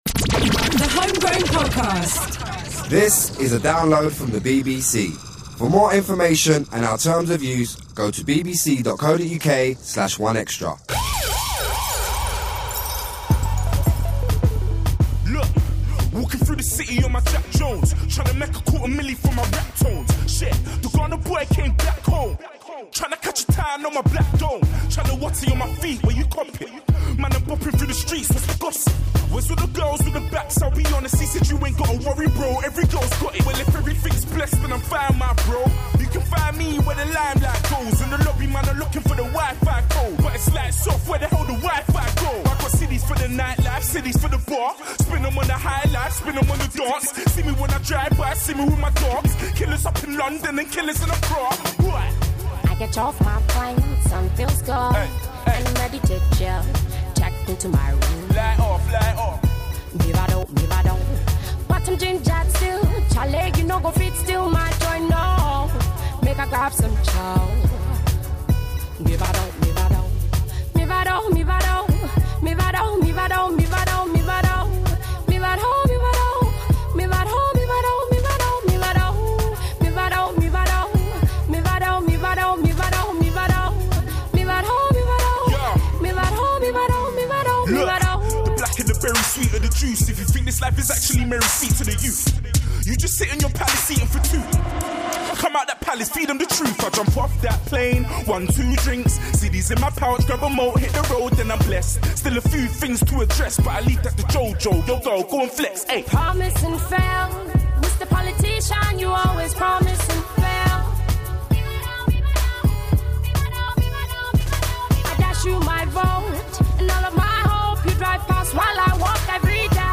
Grime